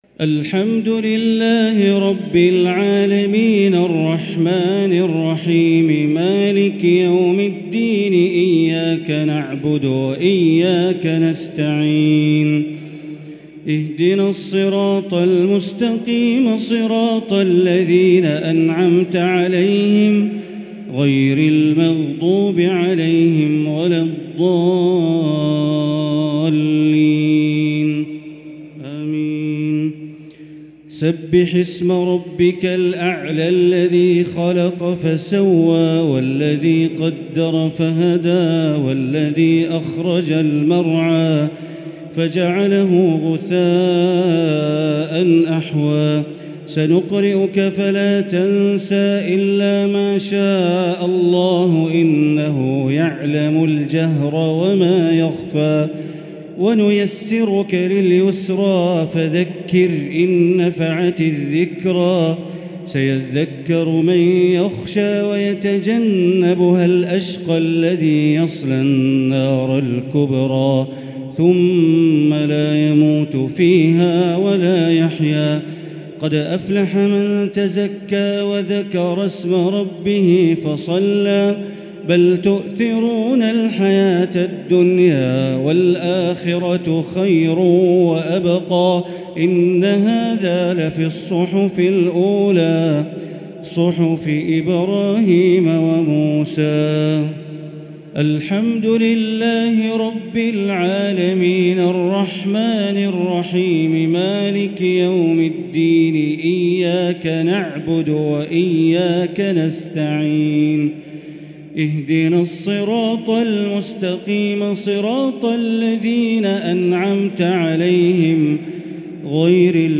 الشفع و الوتر ليلة 4 رمضان 1444هـ | Witr 4 st night Ramadan 1444H > تراويح الحرم المكي عام 1444 🕋 > التراويح - تلاوات الحرمين